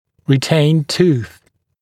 [rɪ’teɪnd tuːθ][ри’тэйнд ту:с]задержавшийся зуб (напр. еще не выпавший молочный зуб); сохранившийся зуб (напр. у взрослых пациентов)